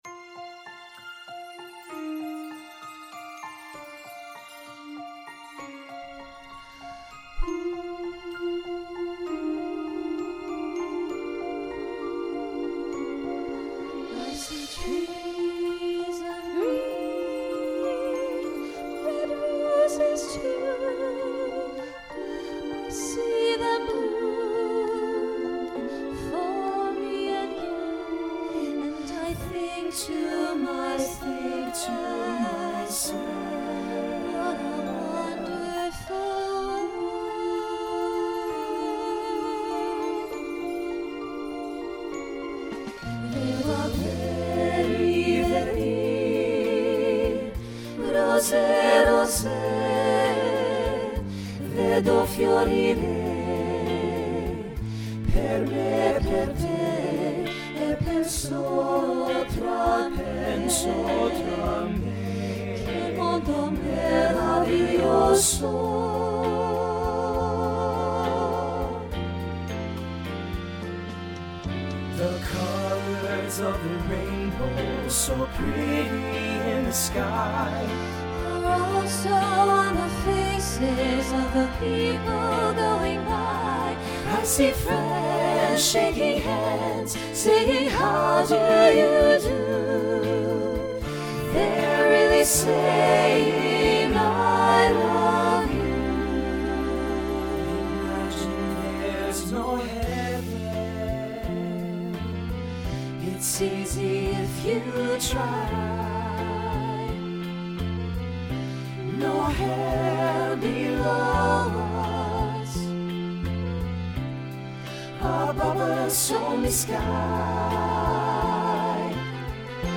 Voicing SATB Instrumental combo Genre Pop/Dance
1980s Show Function Ballad